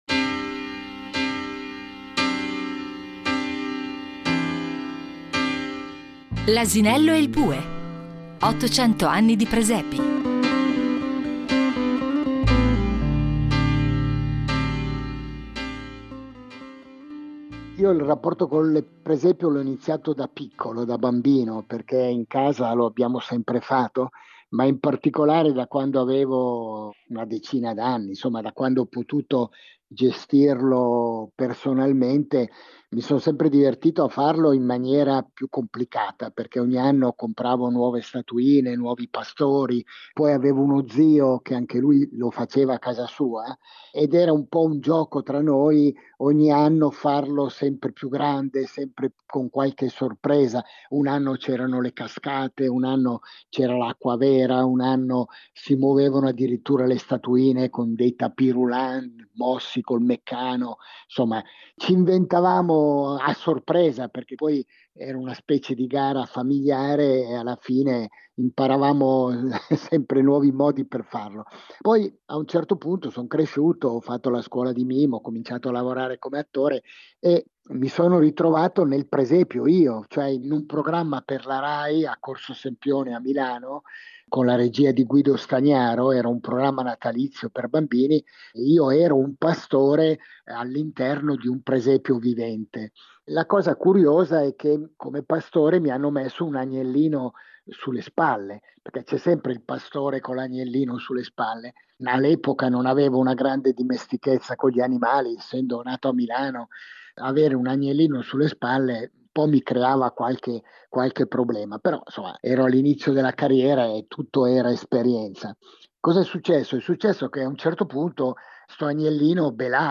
Raccontato da Maurizio Nichetti